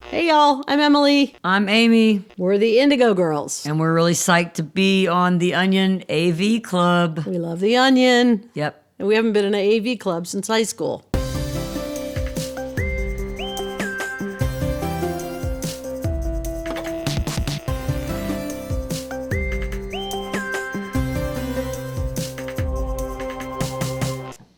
lifeblood: bootlegs: 2020: 2020-06-24: house shows series - the av club - the onion
01. talking with the crowd (0:24)